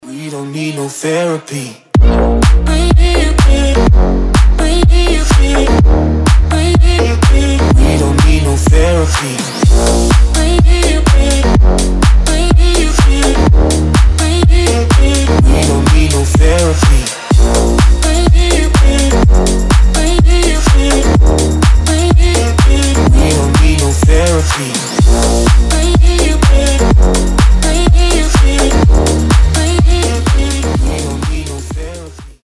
• Качество: 320, Stereo
громкие
Electronic
EDM
дуэт
басы
house
Энергичная хаус-музыка на звонок